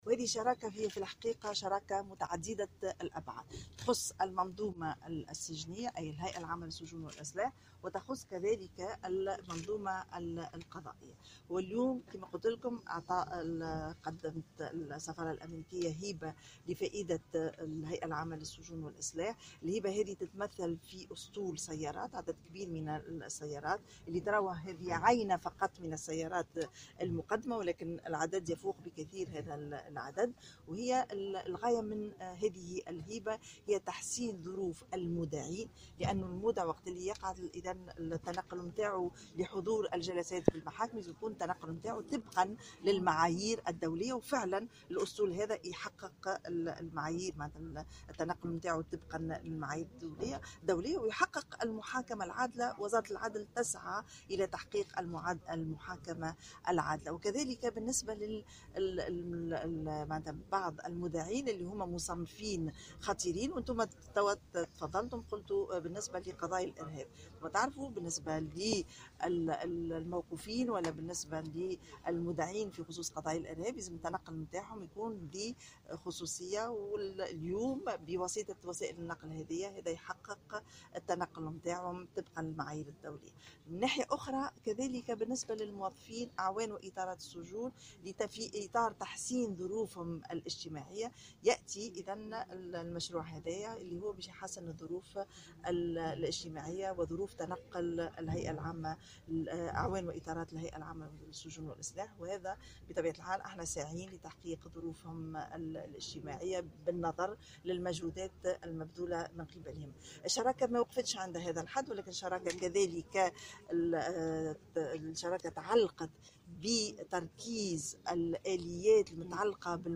تصريح وزيرة العدل السيدة ثريا الجريبي :